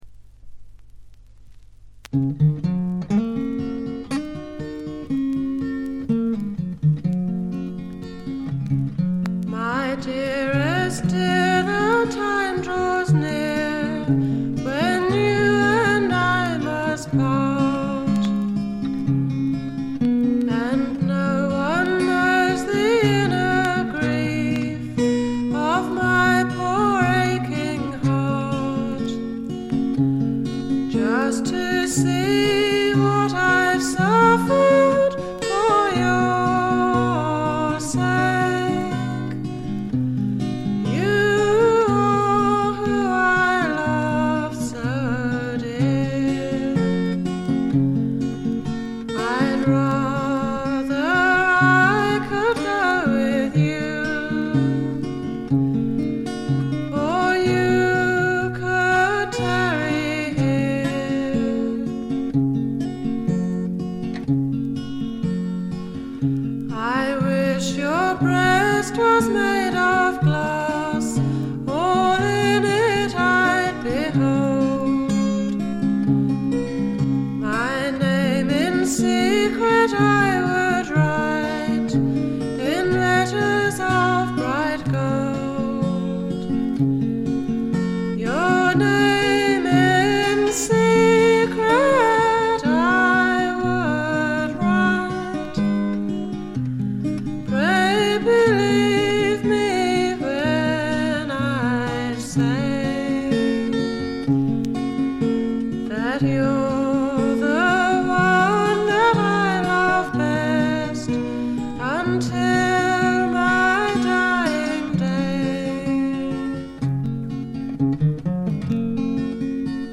バックグラウンドノイズ、軽微なチリプチが聞かれはしますがほとんど気にならないレベルと思います。
試聴曲は現品からの取り込み音源です。